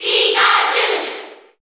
Category: Crowd cheers (SSBU) You cannot overwrite this file.
Pikachu_Cheer_Japanese_SSB4_SSBU.ogg